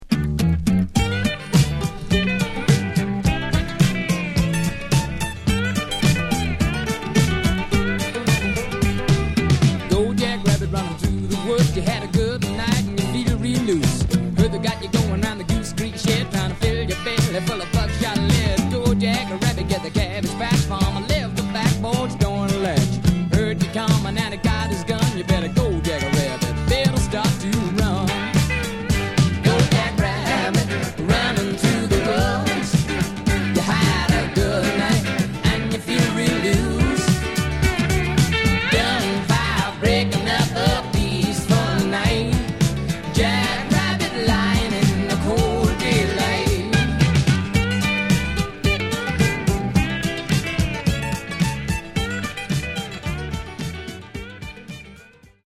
guitar-led, country rocker